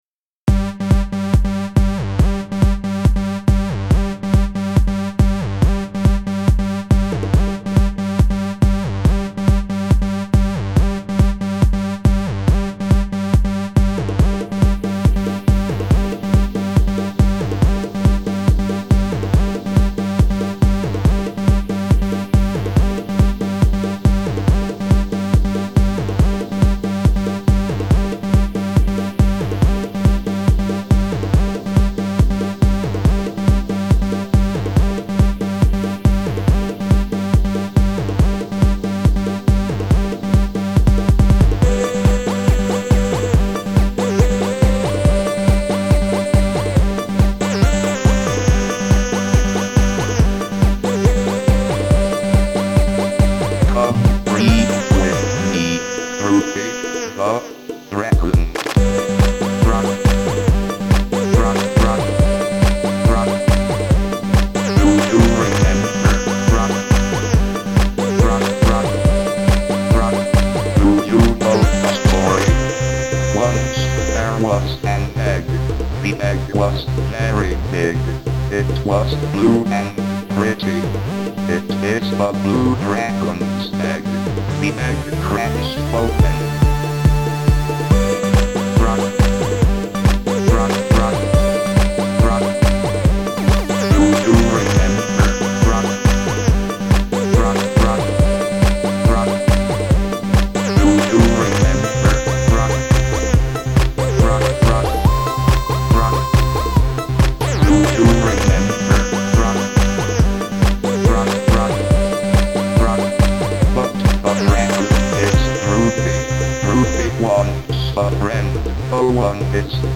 [Dance] Droopy the dragon (MP3)
OK, this is perhaps the silliest song I've ever written. I have samples from an old children's talking book, "Droopy the dragon".